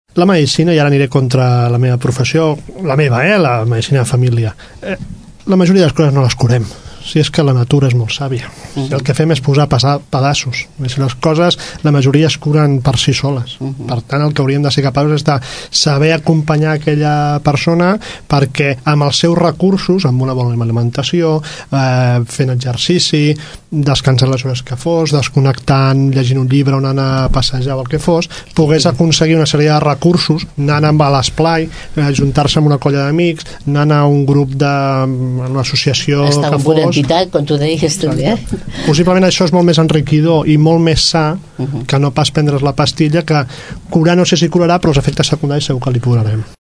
Aquest passat divendres, el programa matinal Ara i Aquí de Ràdio Tordera va acollir una taula rodona sota el títol “Què vol dir tenir salut?”.